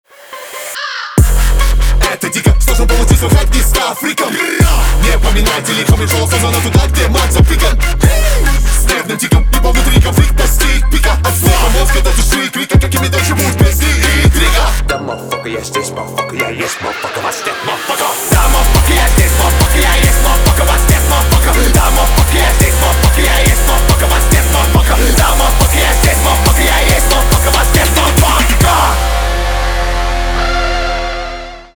русский рэп
битовые , басы , качающие
жесткие , громкие , гитара